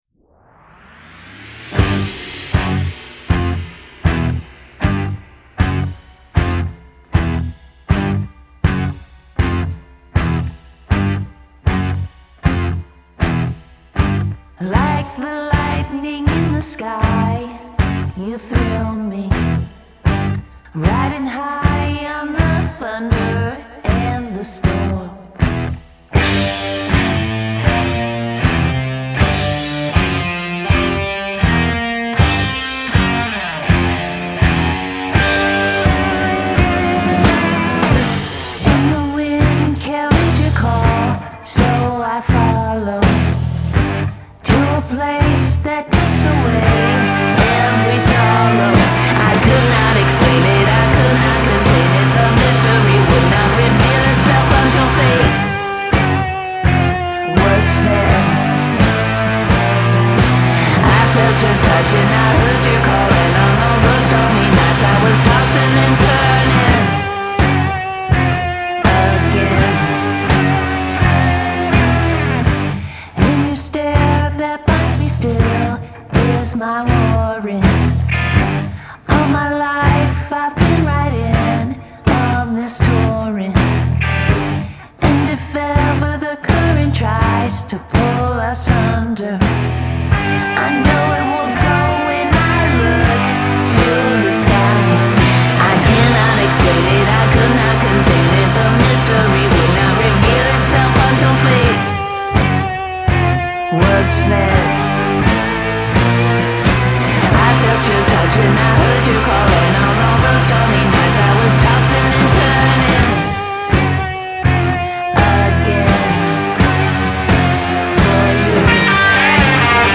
Genre : Rock